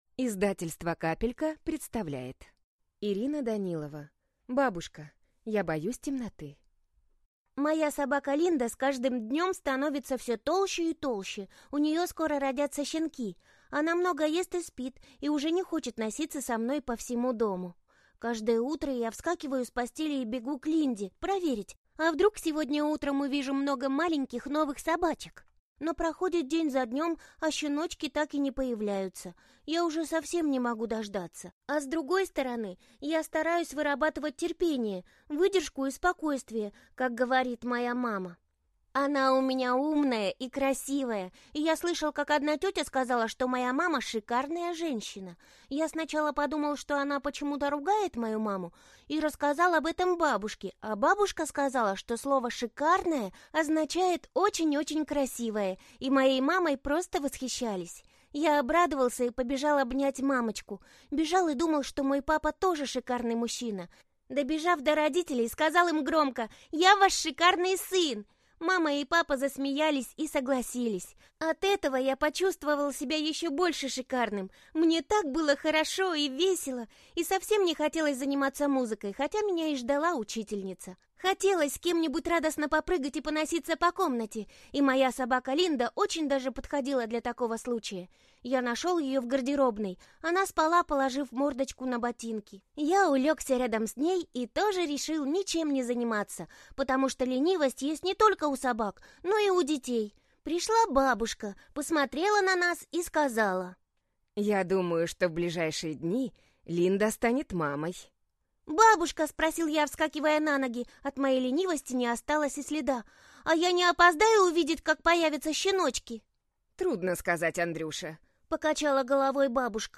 Аудиокнига Бабушка, я боюсь темноты | Библиотека аудиокниг